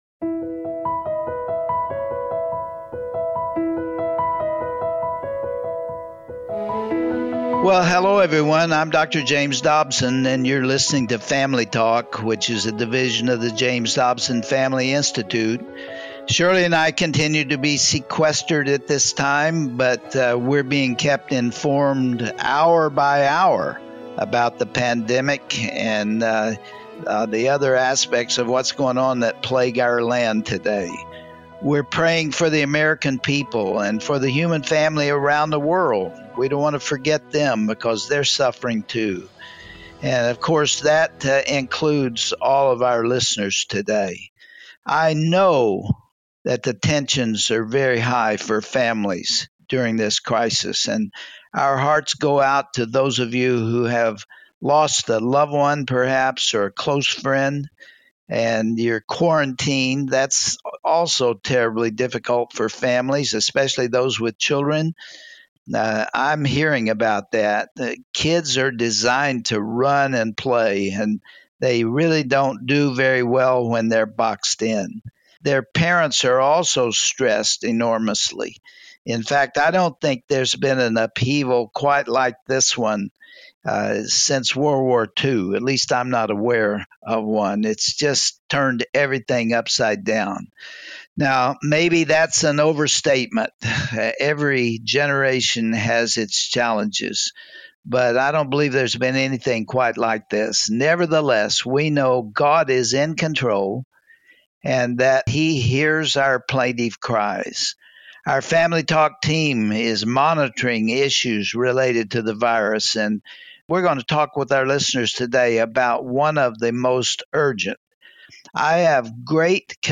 He joined Dr. Dobson by video call and discussed his taxing schedule and described the difficulty of being quarantined away from his family.